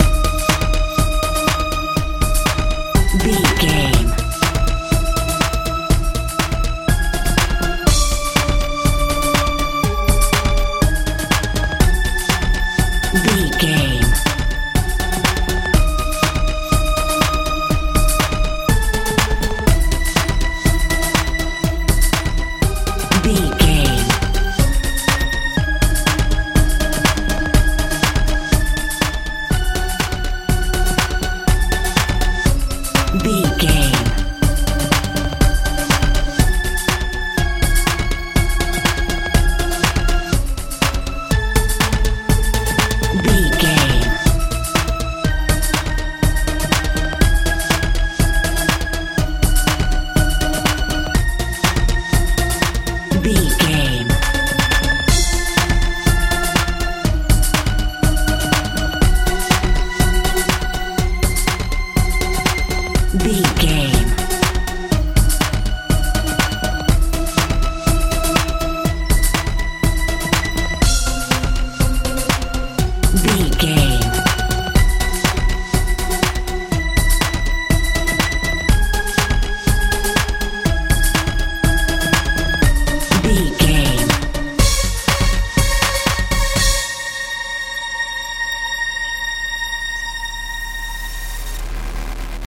modern dance
Ionian/Major
strange
mystical
bass guitar
synthesiser
drums
80s
tension
poignant
futuristic
bouncy